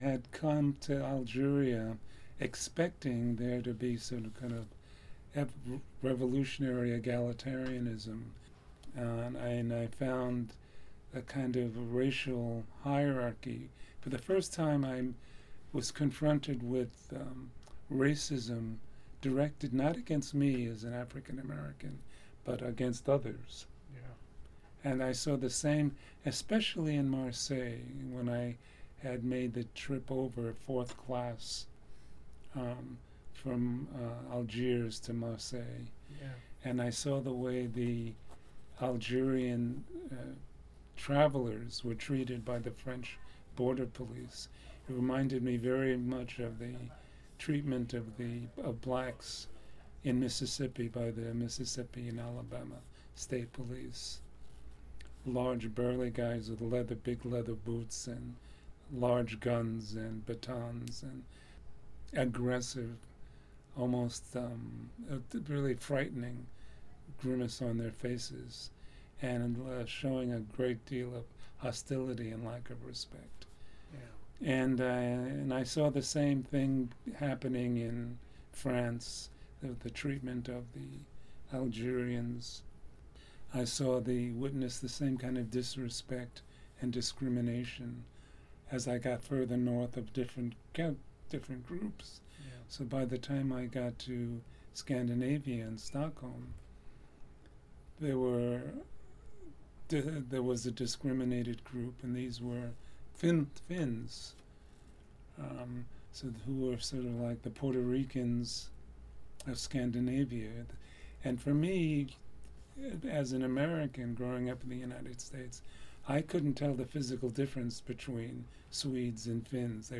Dr. Sidanius describes this journey and how it contributed to his future theory in the clip below: